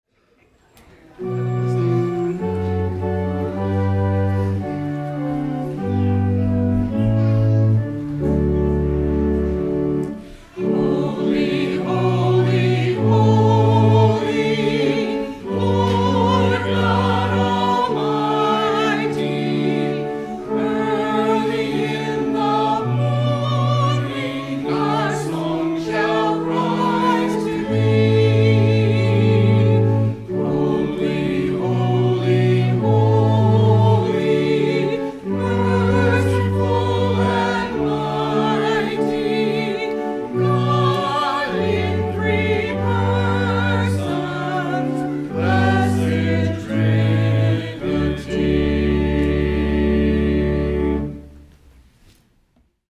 Home Music Anthem Holy, Holy, Holy!